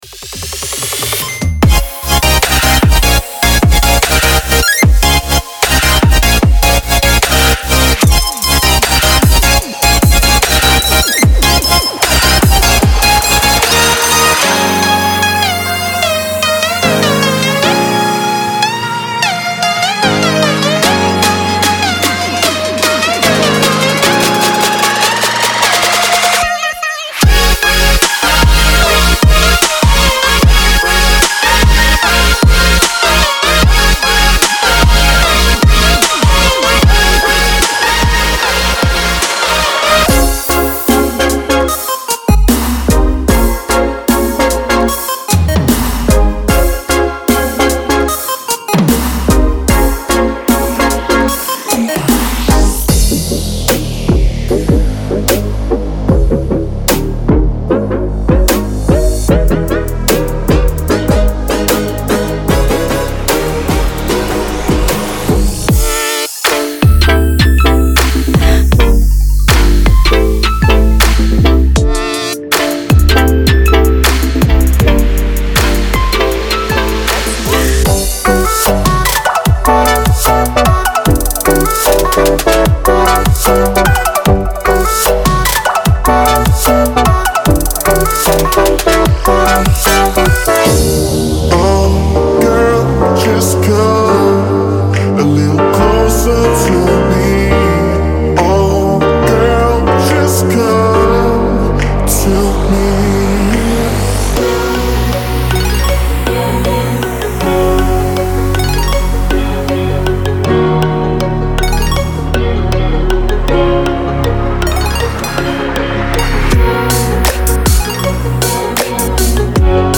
3. Future Bass
– 203 Chords
– 068 Leads
– 029 Pads